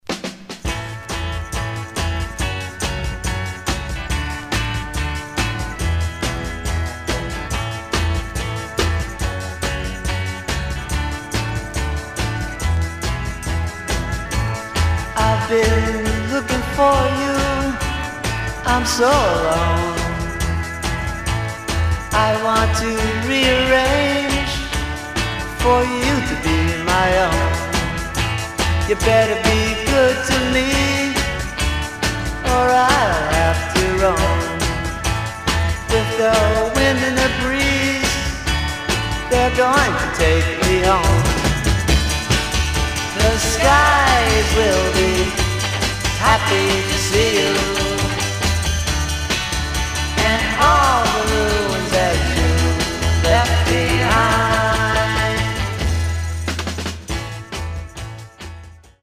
Stereo/mono Mono
Garage, 60's Punk ..........👈🏼 Condition